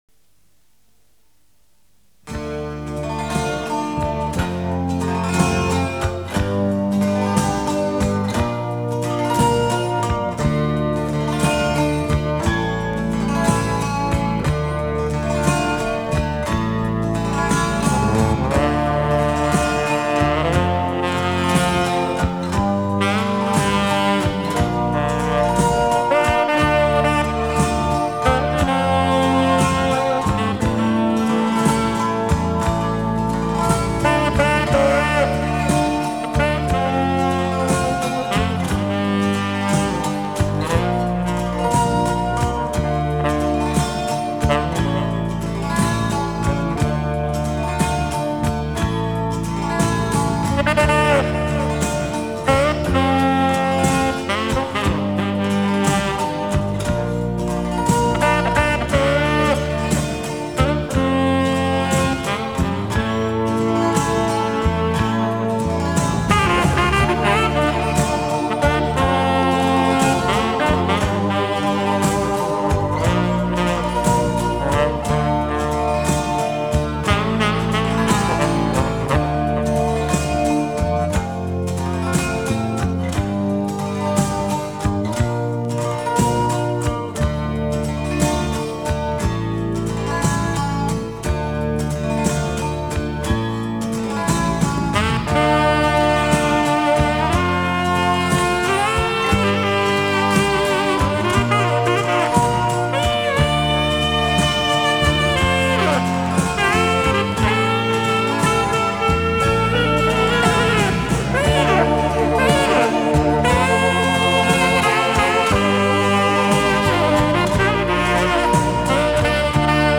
Не знаю насчет кассет, эта запись есть в сети подписана также невнятно saxophone - The House Of The Rising Sun (Instrumental) качество и получше и похуже.